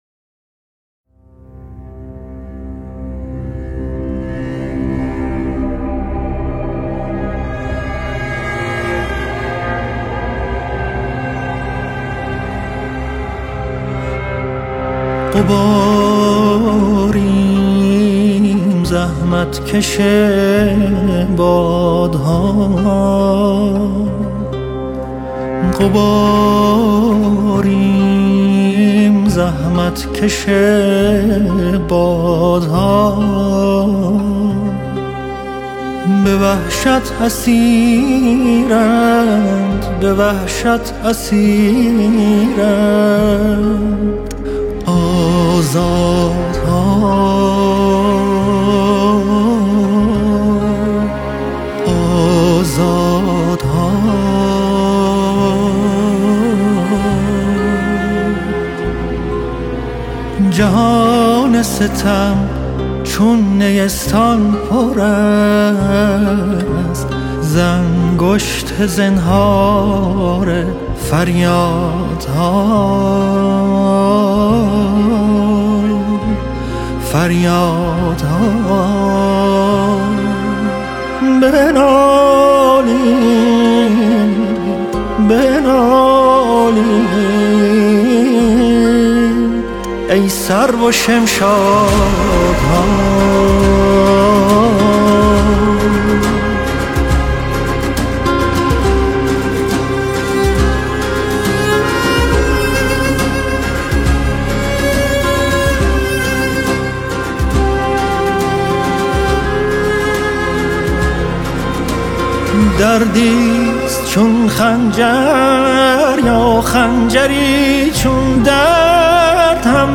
موسیقی سنتی و تلفیقی